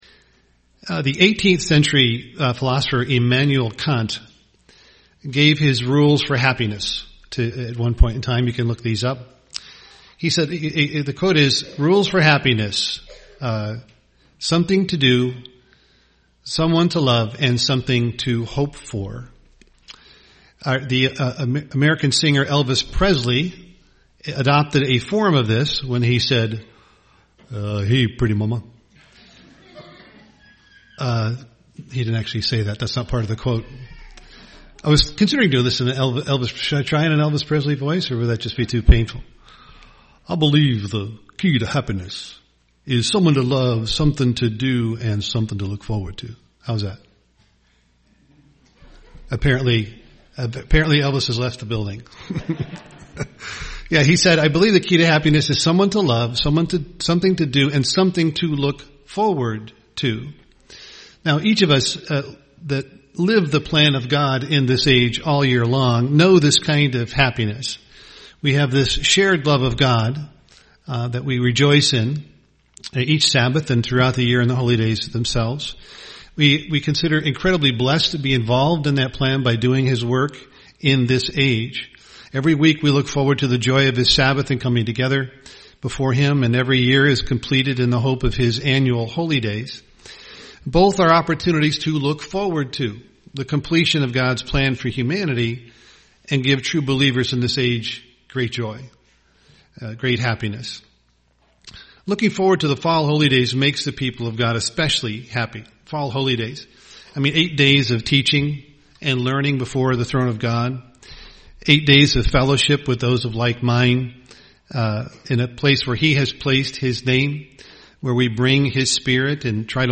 Holy Days UCG Sermon Studying the bible?